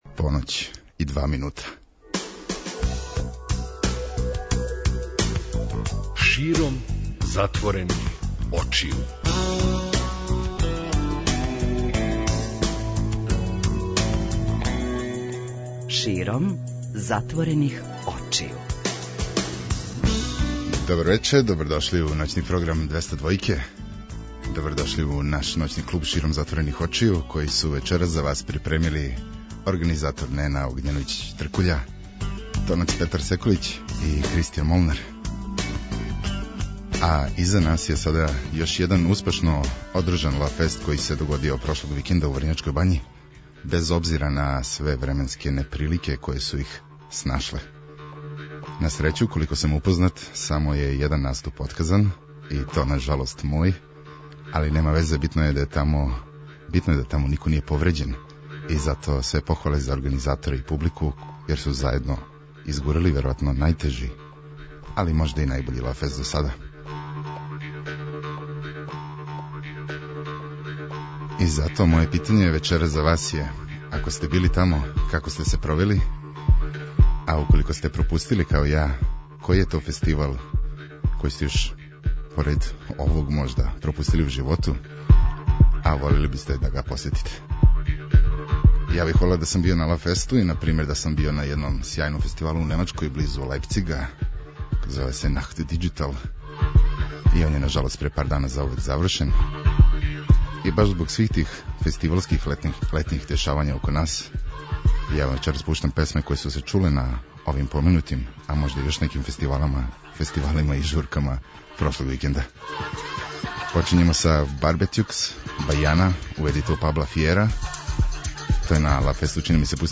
преузми : 53.42 MB Широм затворених очију Autor: Београд 202 Ноћни програм Београда 202 [ детаљније ] Све епизоде серијала Београд 202 Тешке боје Устанак Устанак Устанак Брза трака